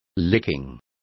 Complete with pronunciation of the translation of lickings.